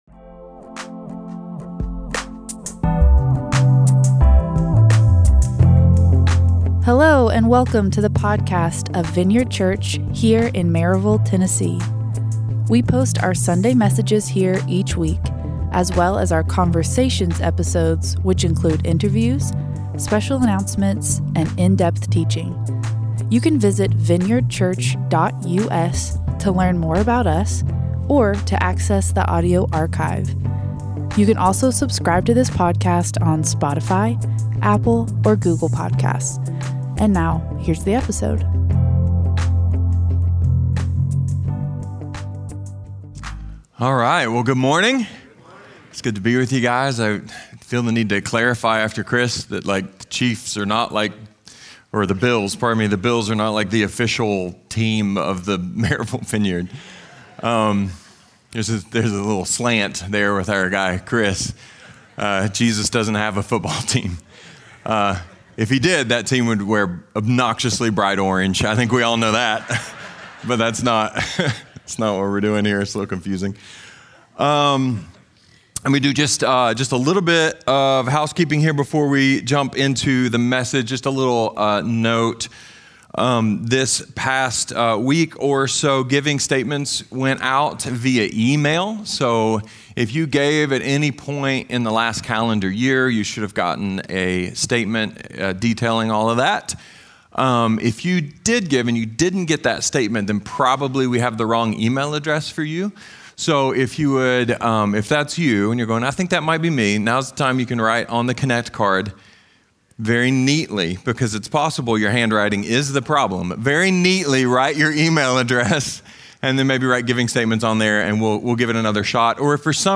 A sermon about how a frequently-read book of the bible, and an often-neglected book of the bible, are designed work together.